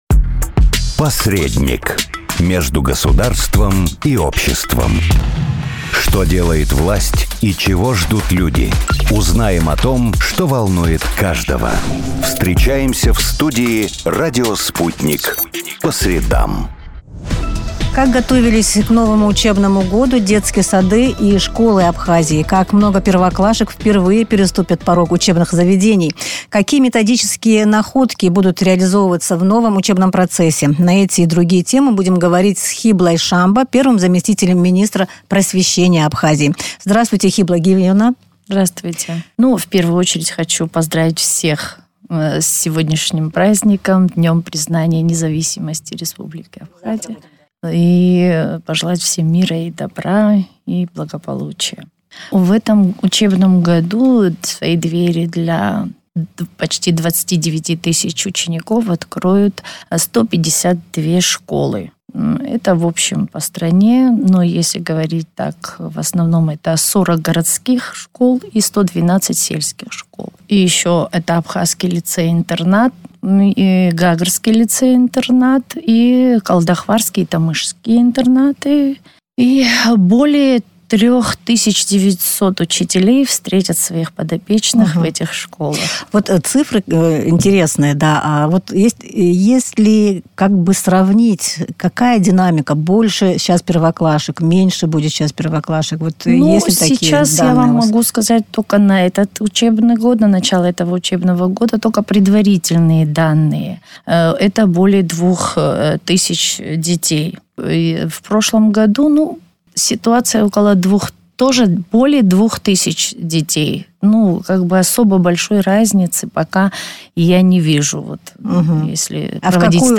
Сколько школ распахнут в этом году свои двери перед учениками, какое количество первоклашек ждут учителя, как обстоит дело с кадрами? На эти и другие вопросы в преддверии 1 сентября в эфире радио Sputnik ответила Хибла Шамба.